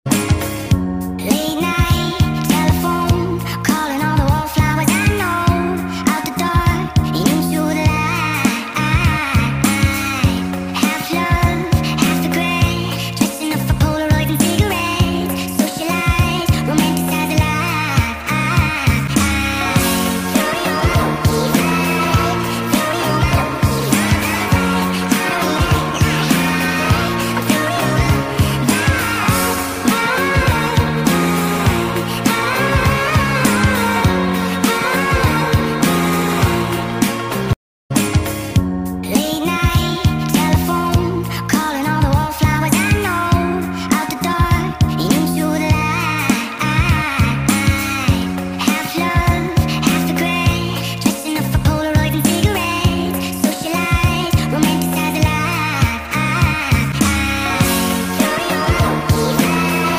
спокойные